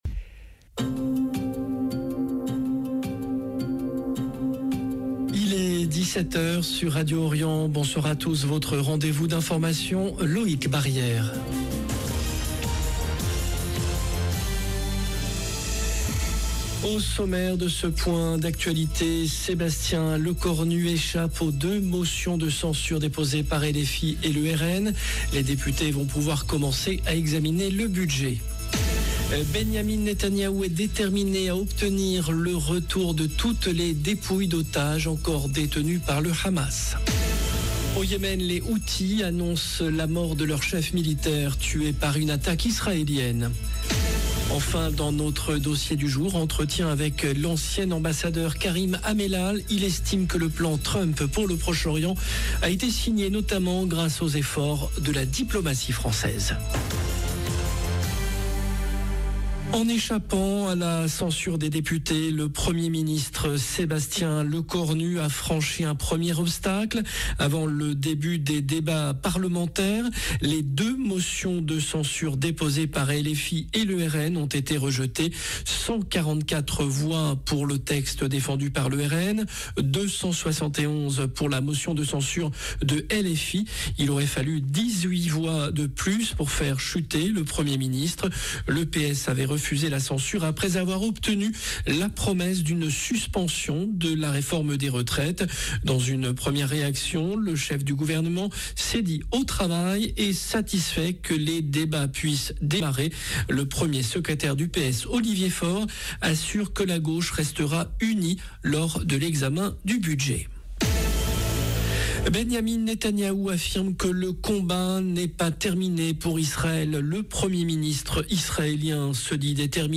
JOURNAL DE 17H DU 16/10/2025